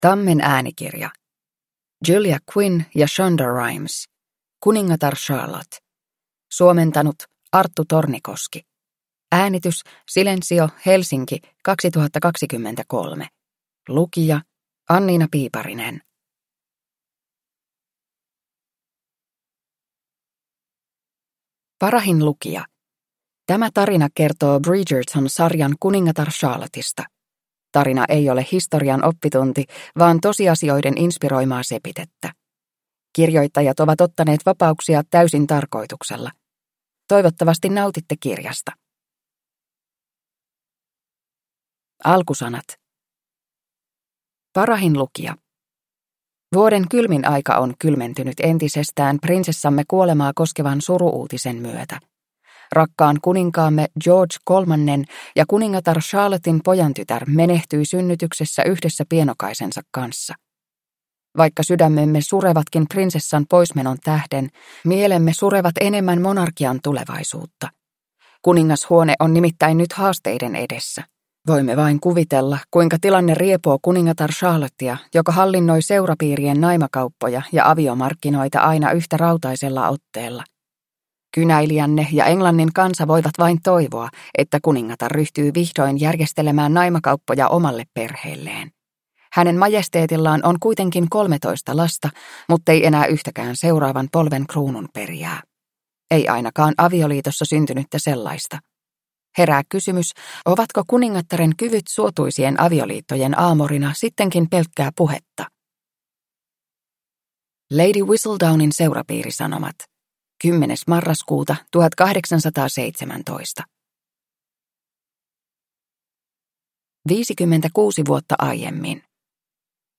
Bridgerton: Kuningatar Charlotte – Ljudbok – Laddas ner